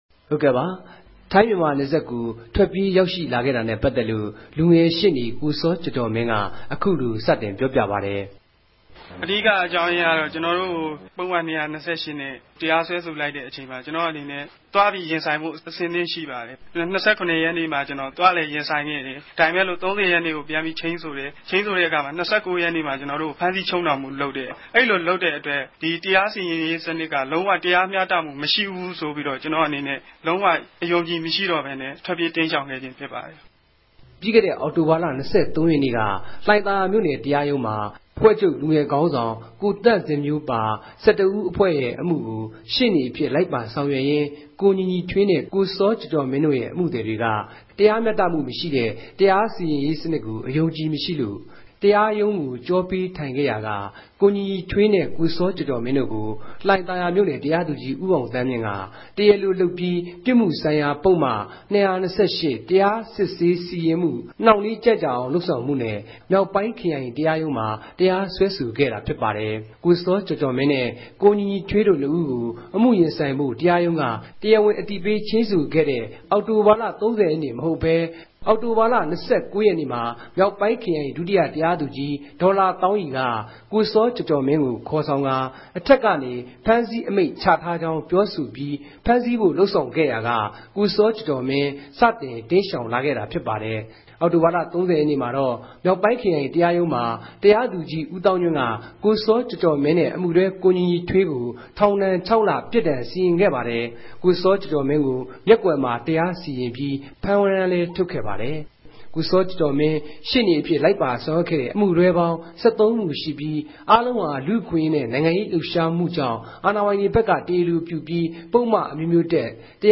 သတင်းစာရြင်းလင်းပြဲ။